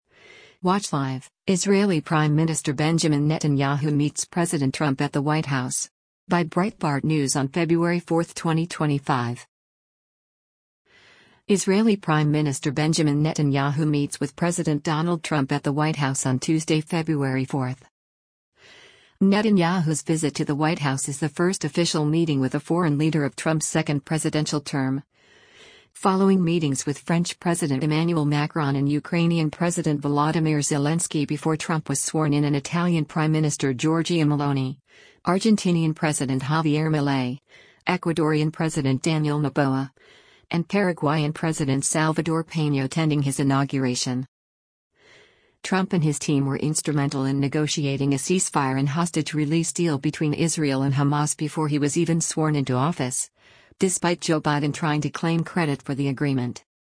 Israeli Prime Minister Benjamin Netanyahu meets with President Donald Trump at the White House on Tuesday, February 4.